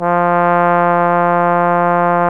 Index of /90_sSampleCDs/Roland L-CDX-03 Disk 2/BRS_Trombone/BRS_Tenor Bone 2